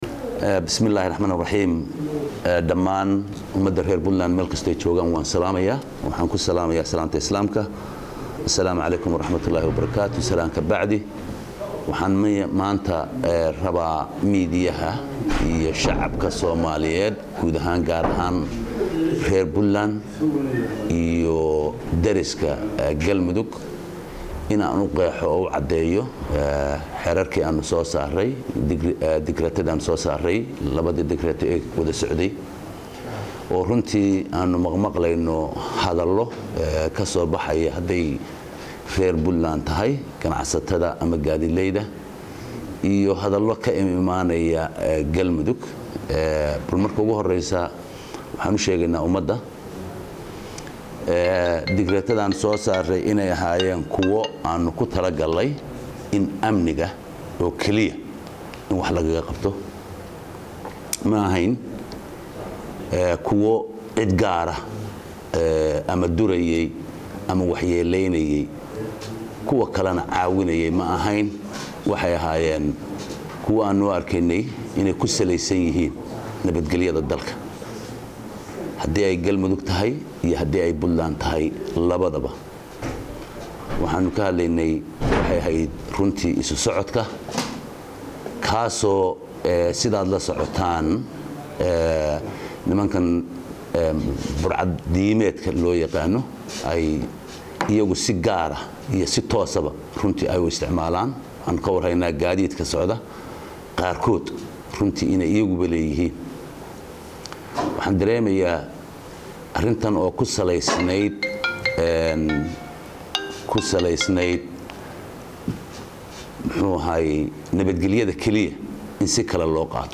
Kusimaha madaxweynaha, Dowladda Puntland Eng:C/xakiin Xaaji cumar Camay, saxaafadda lahadlaya Maanta, Axad,28,Augu,2016.
Halkaan ka dhagayso hadalkii Madaxweynaha ku xigeenka.